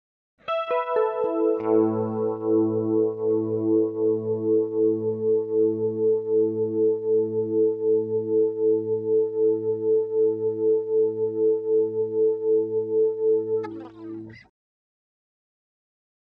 Electric Guitar Harmonic Arpeggio With Flange 3